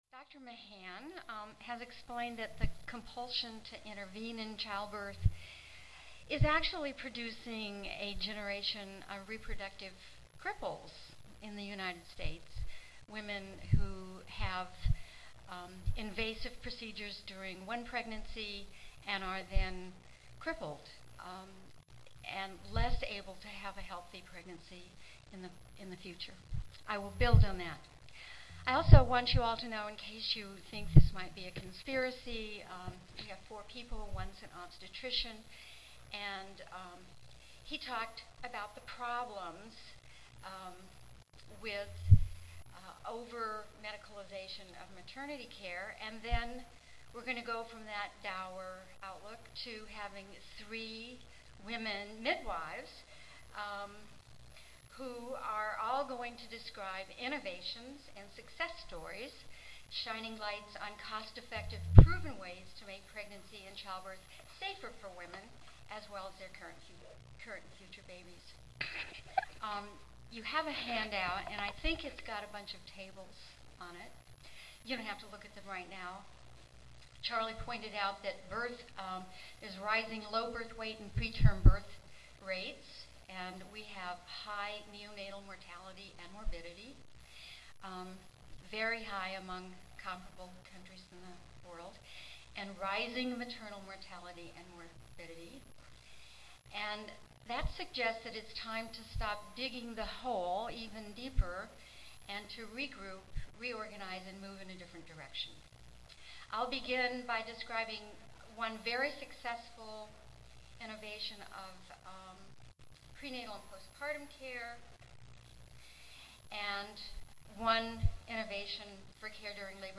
Access Beyond Health Insurance:The Politics and Policy of Maternal Child Health Tuesday, November 6, 2007: 2:30 PM Oral Everyone knows that our health care system is broken.